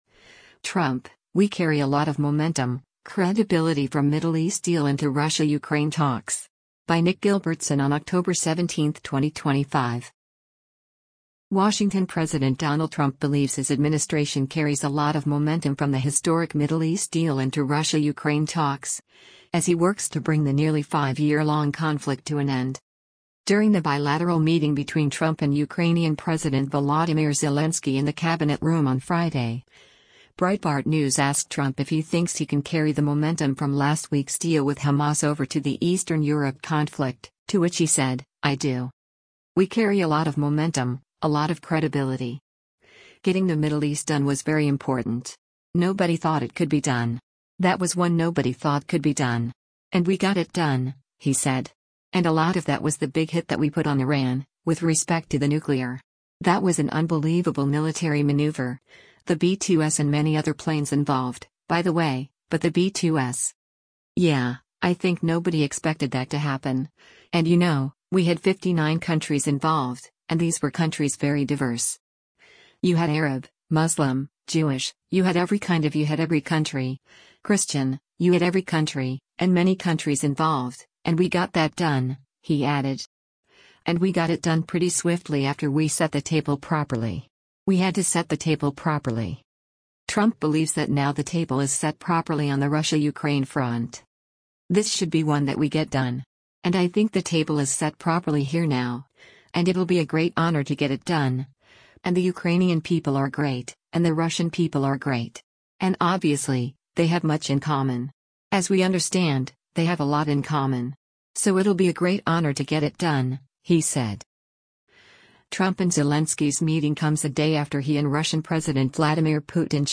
During the bilateral meeting between Trump and Ukrainian President Volodymyr Zelensky in the Cabinet Room on Friday, Breitbart News asked Trump if he thinks he can carry the momentum from last week’s deal with Hamas over to the Eastern Europe conflict, to which he said, “I do.”